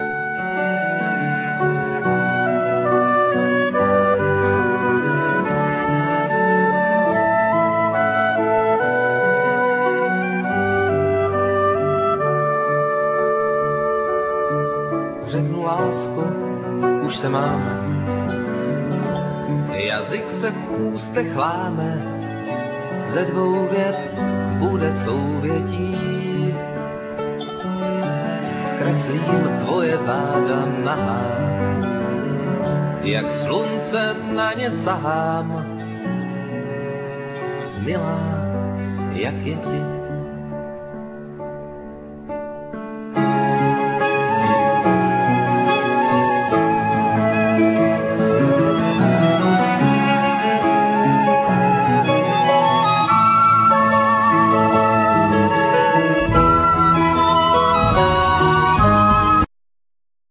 Piano,Keyboards,Guitar,Trumpet,Vocal,etc
Cello,Saxophone,Vocal,etc
Flute,Piano,Keyboards,etc
Drums,Percussions,Vocal,etc